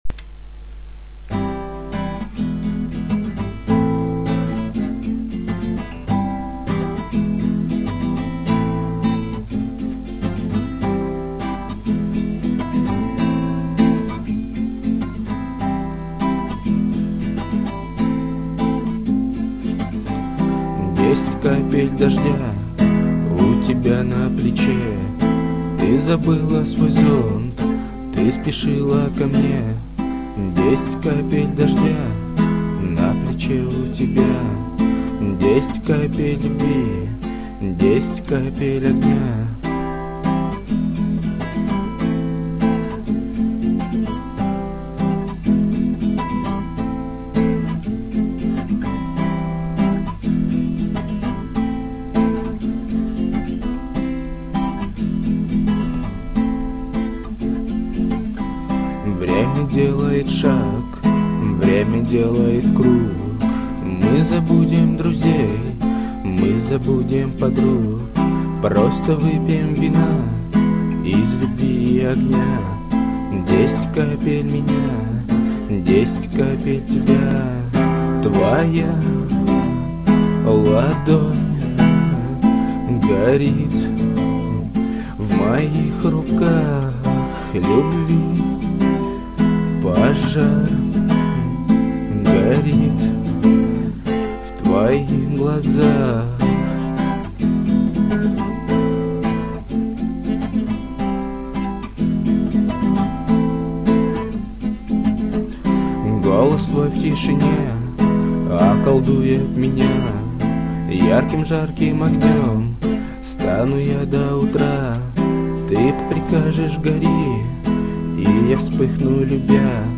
а голос и вправду завораживающий.. winked